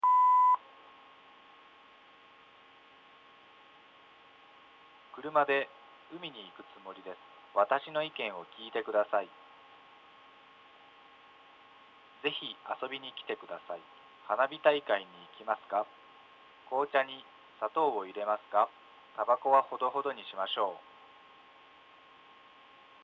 • SC(SignalCosme)が筐体マイクの集音する背景騒音を低減します。
PattXX_LサイレントR男性_ECON_SCON PCステレオ信号出力のLch無音、Rch男声の組み合わせです。
ECとSCが効いています。 エコーはないのでSCの効果がわかります。
Port1OutをWAVファイルで録音しmp3フォーマットに変換しました。
Patt01_LサイレントR男性_ECON_SCON.mp3